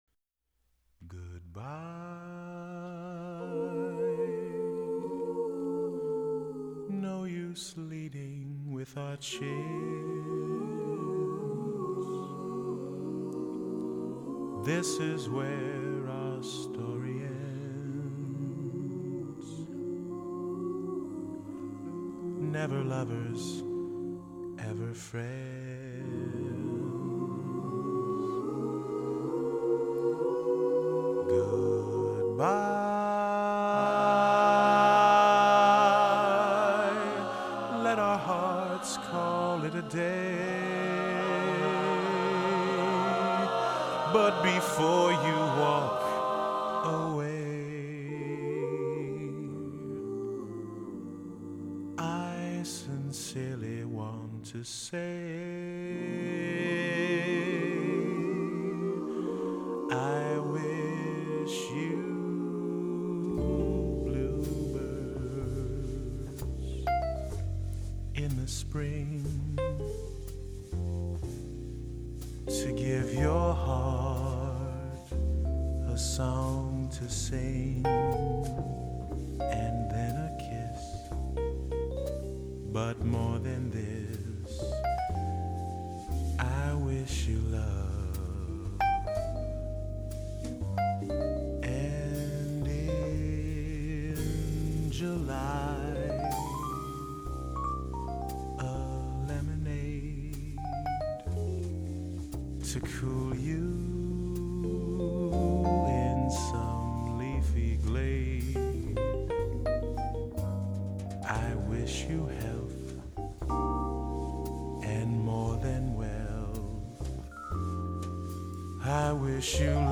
SATB & rhythm